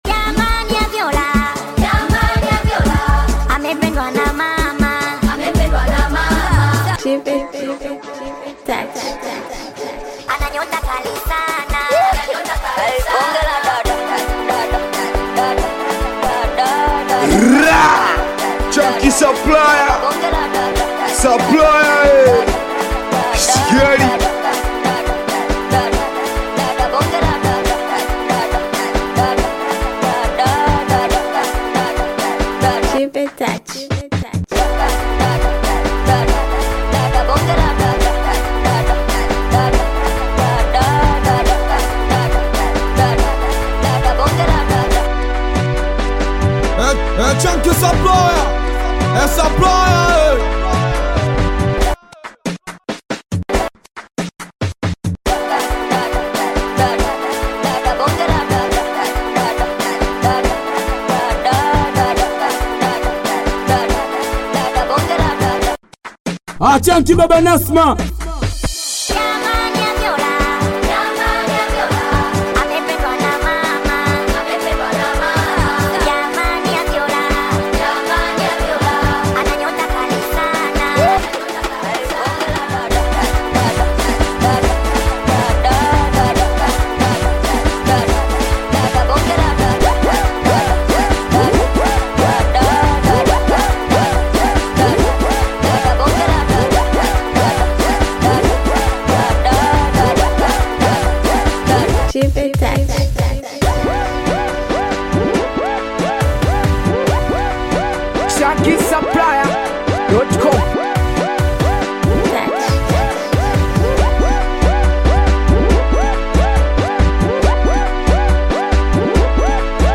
SINGELI BEAT